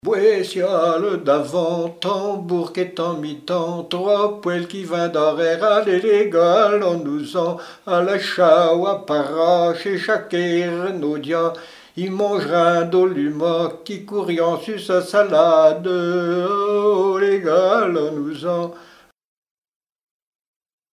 Pibolage ou chant aux boeufs
Appels de labour, tiaulements, dariolage, teurlodage, pibolage
couplets vocalisés
Pièce musicale inédite